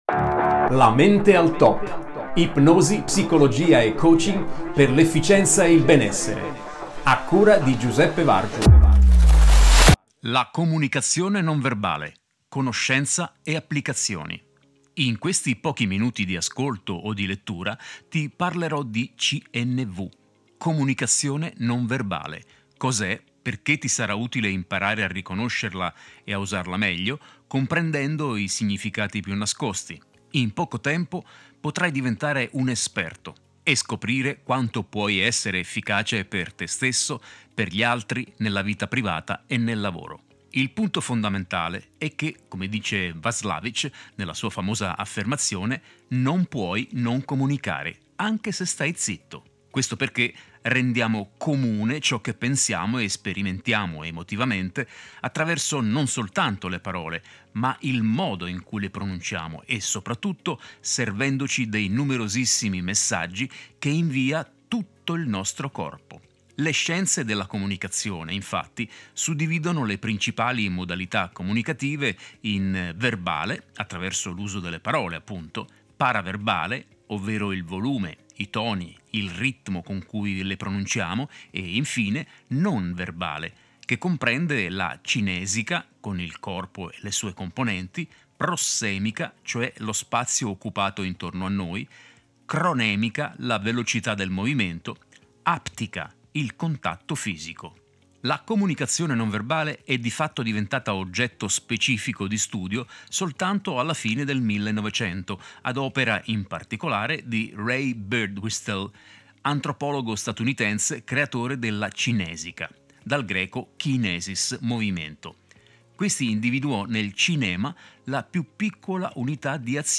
Versione audio dell'articolo 1.